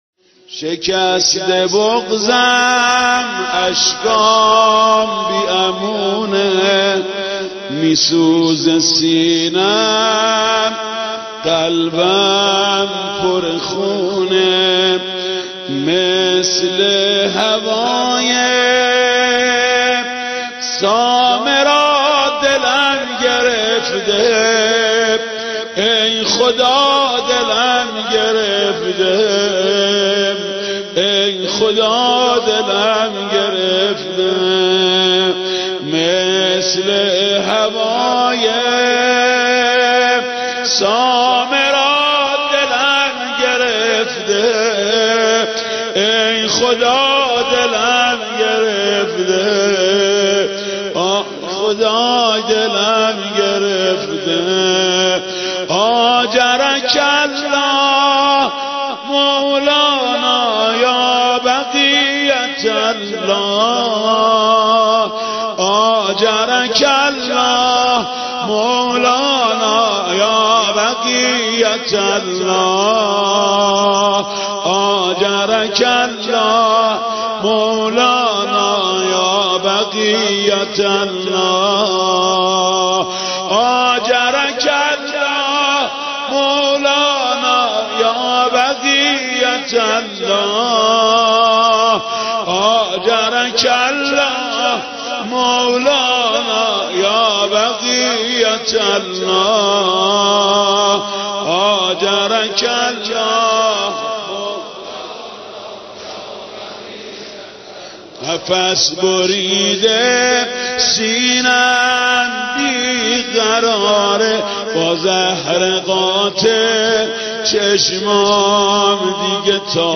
ویژه شهادت امام حسن عسکری (زمینه)